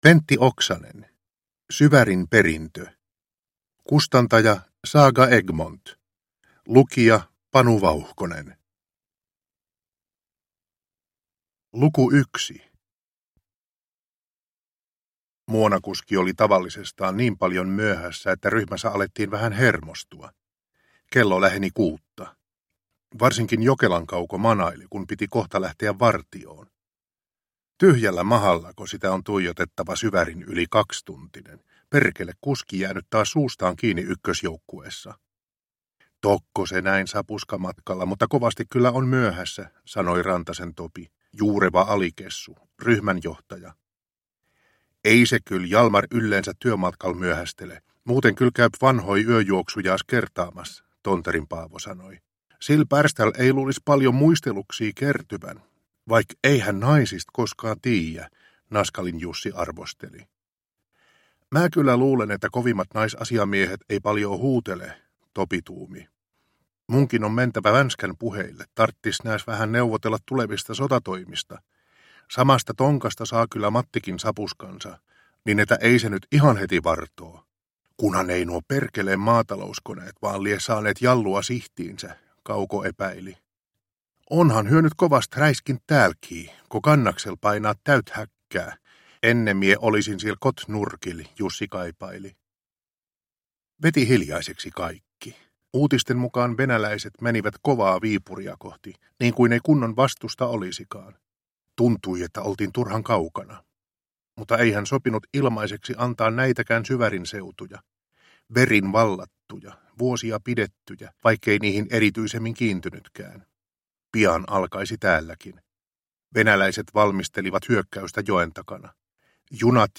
Syvärin perintö – Ljudbok